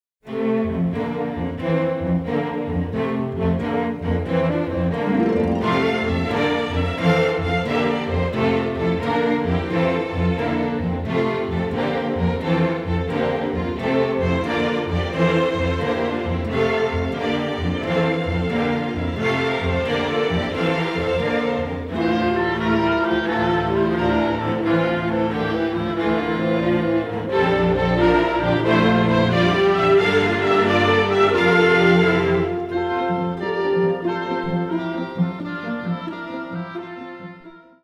tuneful Americana